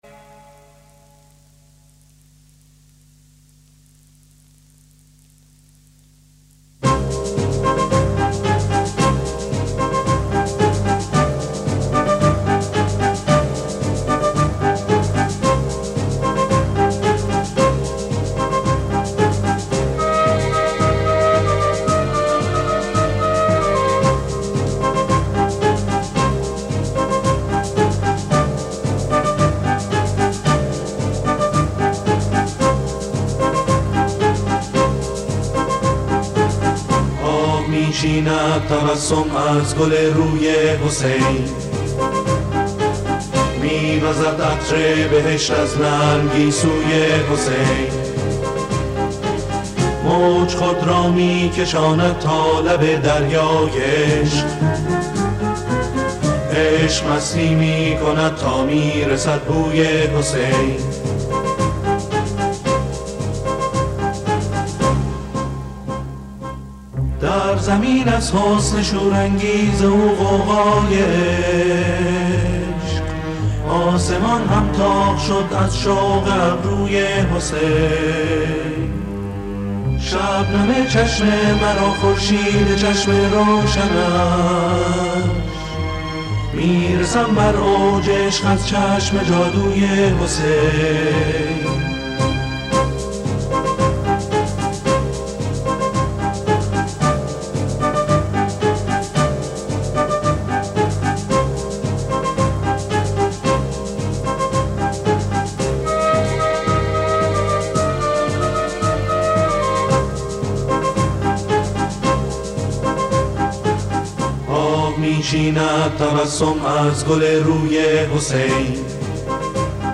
سرود ها